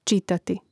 čìtati čitati gl. nesvrš. prijel.